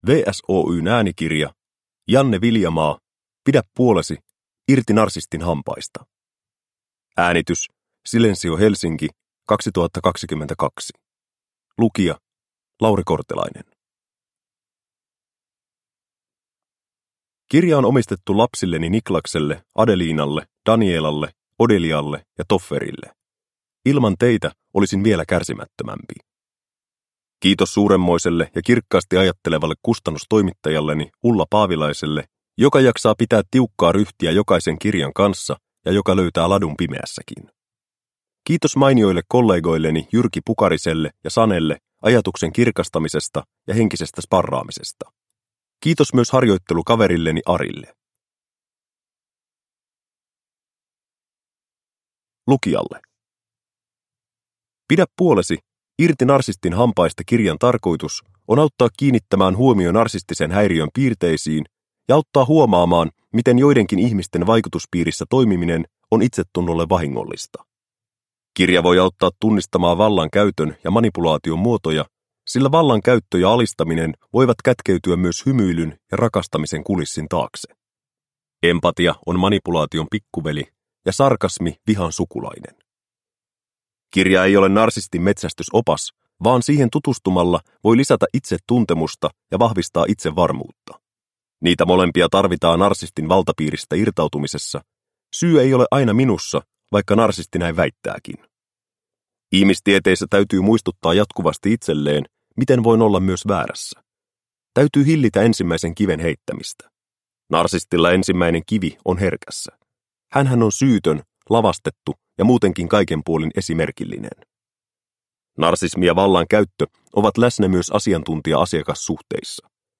Pidä puolesi - irti narsistin hampaista – Ljudbok – Laddas ner